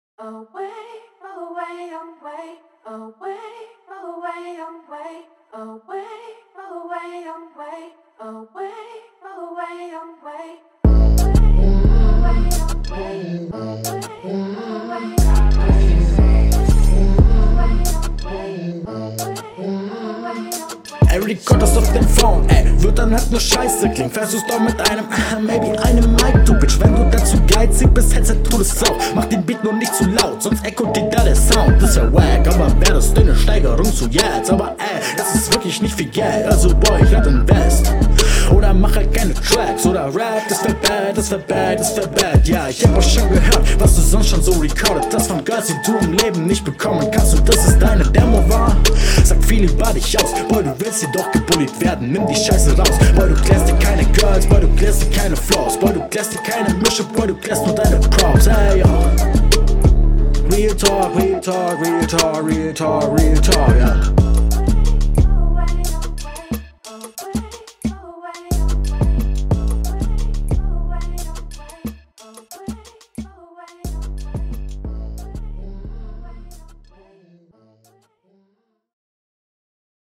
Du legst mit deinem üblichen Trap-Style eigentlich gut los. Flowlich und Raptechnisch total in Ordnung.